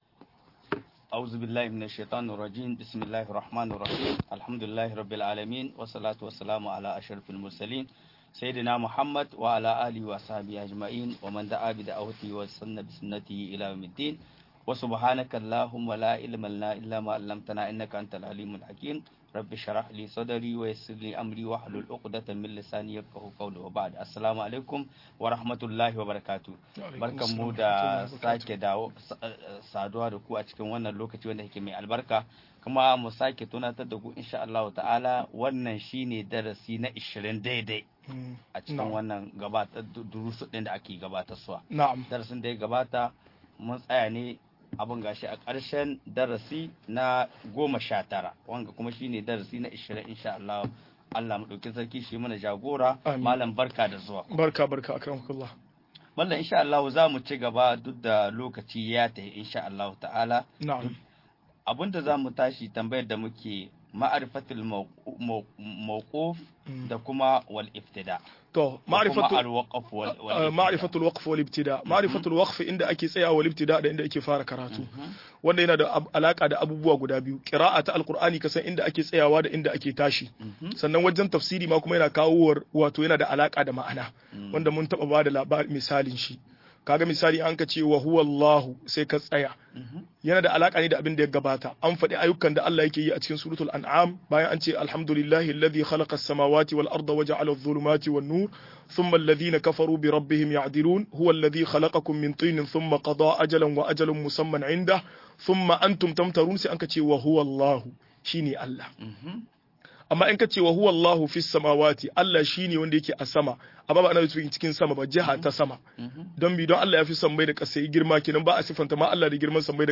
MUHADARA